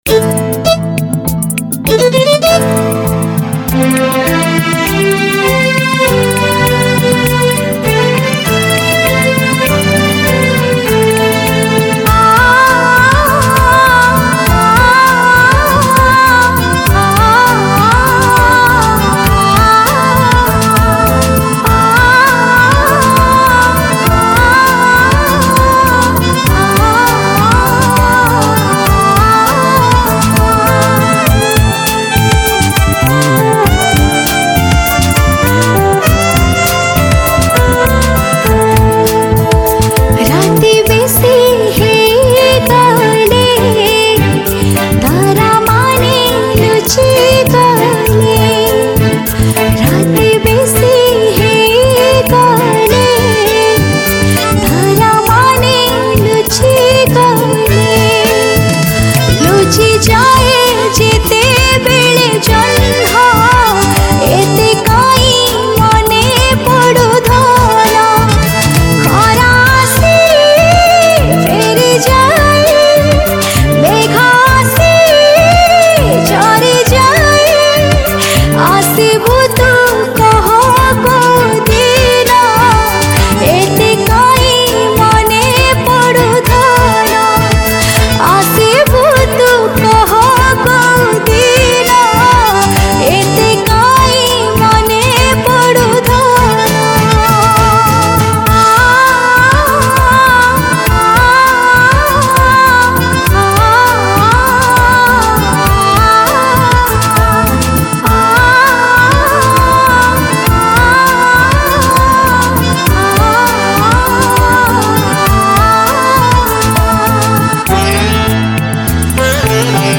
Sad Romantic Song